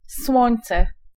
Ääntäminen
IPA: [s̪wɔ̃ĩ̯n̪.t͡s̪ɛ]